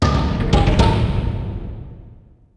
slammer1.wav